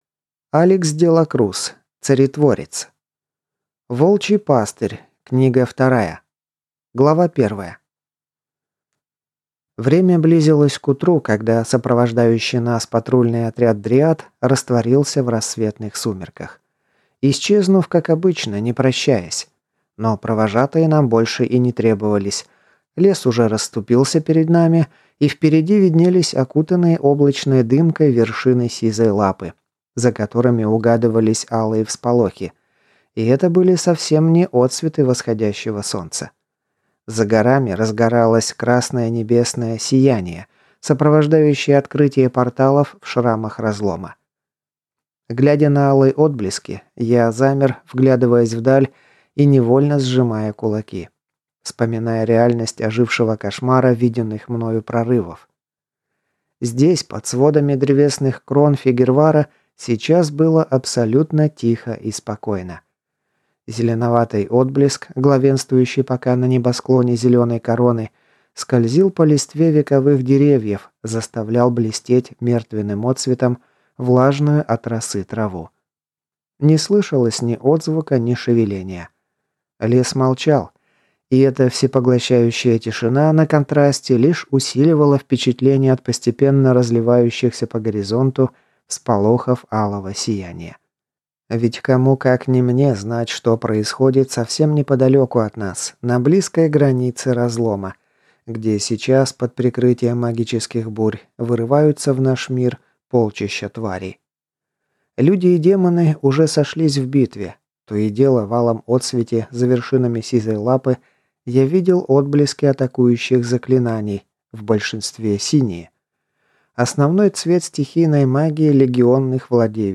Аудиокнига Царетворец. Волчий пастырь. Книга вторая | Библиотека аудиокниг